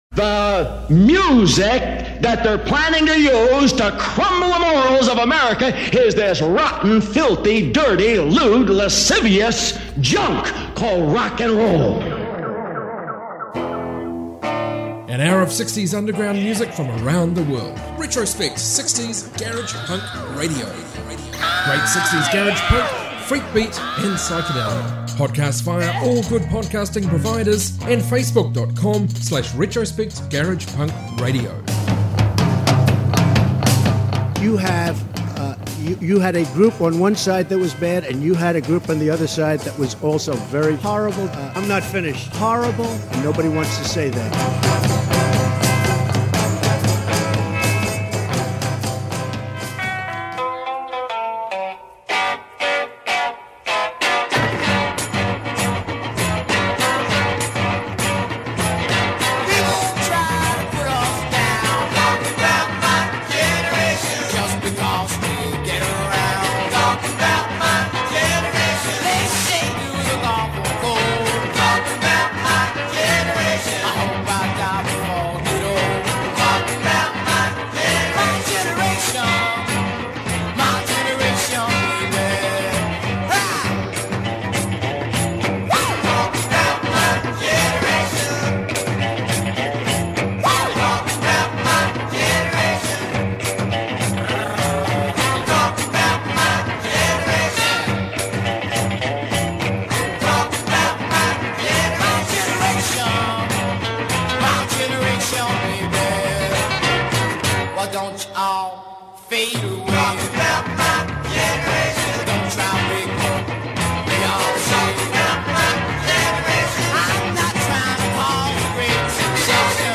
60s garage music